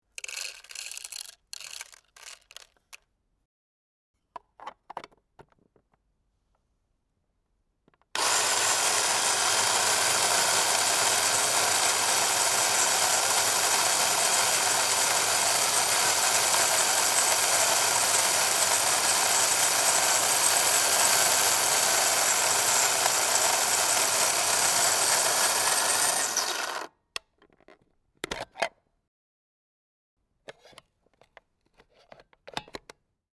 Krups, Type 222 coffee grinder